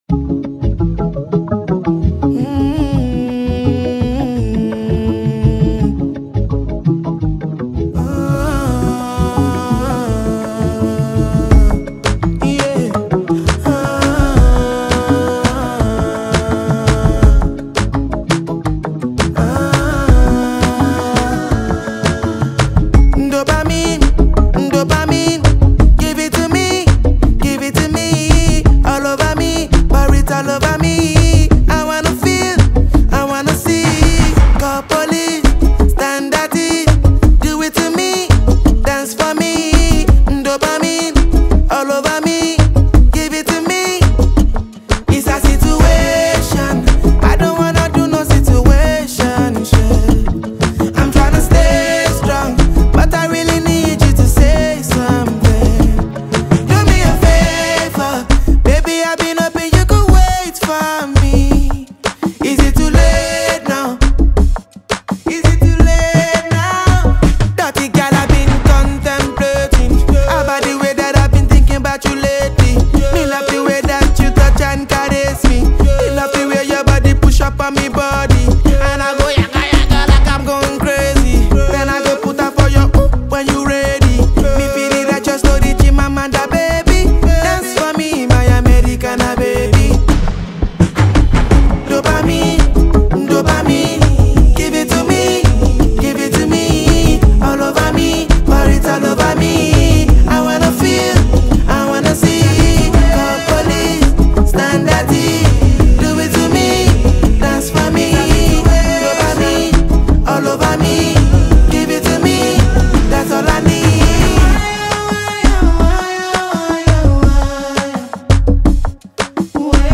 Foreign Music